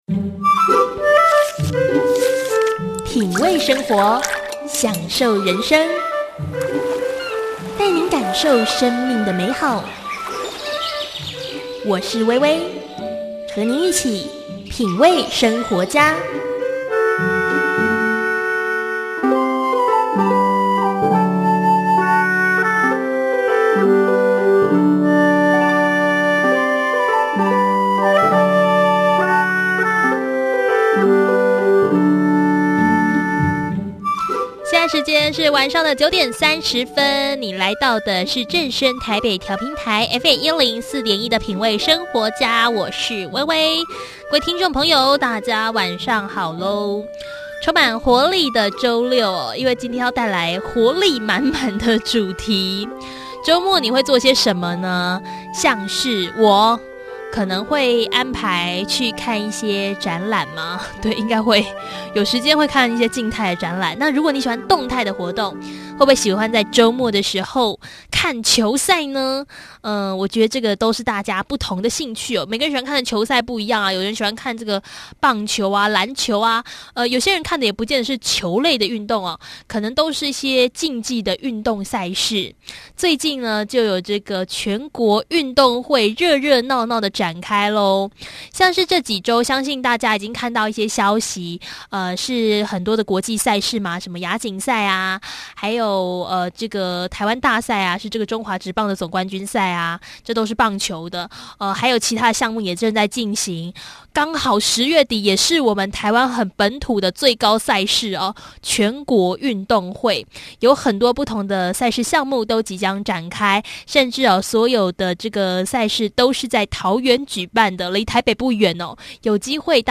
受訪者：桃園市政府新聞處詹賀舜處長 108年全國運動會，10月19日至10月24日在桃園熱鬧登場，有田徑、體操